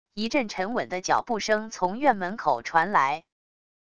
一阵沉稳的脚步声从院门口传来wav音频